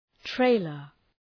{‘treılər}